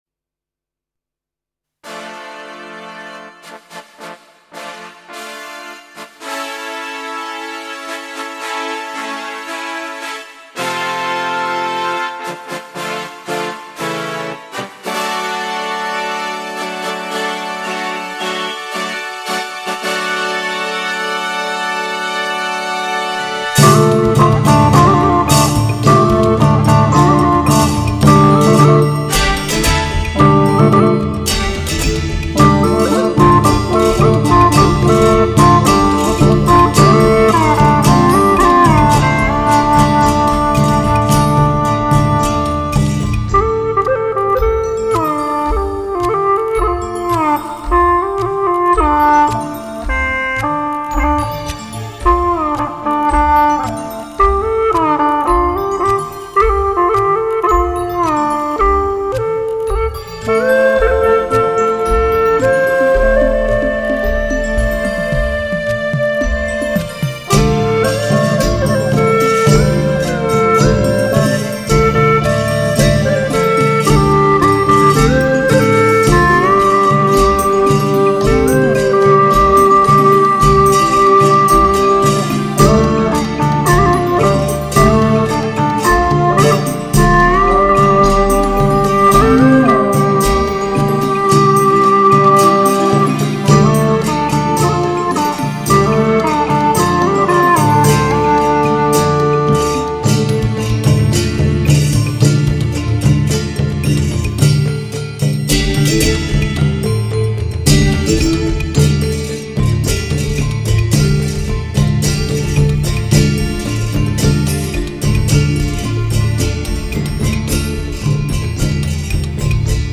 调式 : F 曲类 : 民族